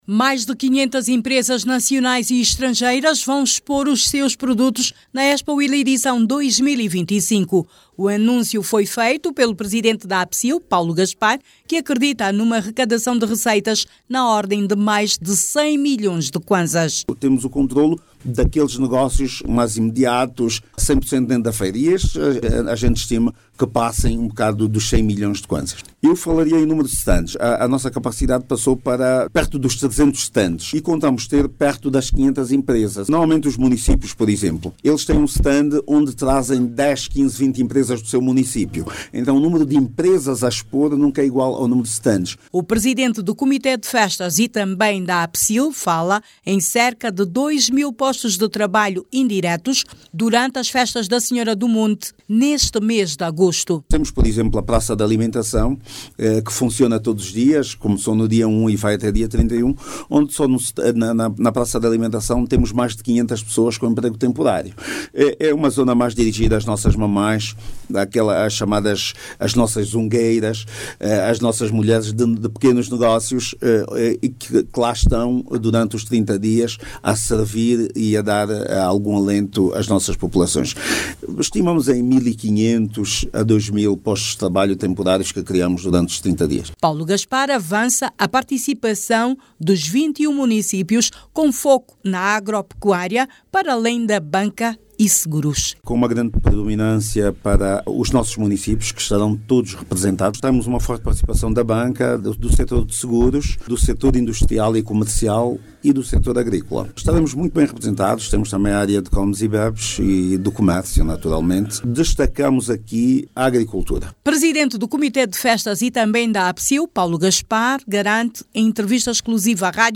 Mais de quinhentas empresas nacionais e estrangeiras vão participar da expo Huila-2025 que acontece de 20 a 24 deste mês na cidade do Lubango. A organização da Expo-Huila, pretende realizar negócios na ordem dos cem milhões de kwanzas. Clique no áudio abaixo e ouça a reportagem